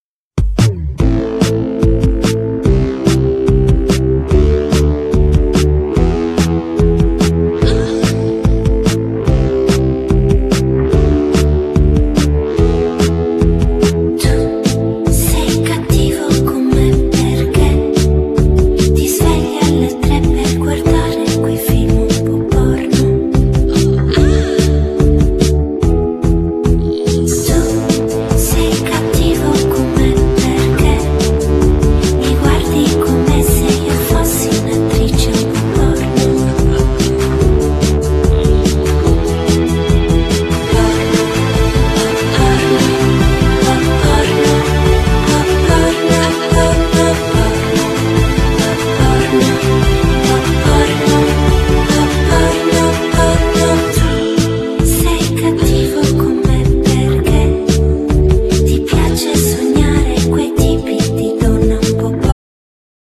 Genere : Electro Pop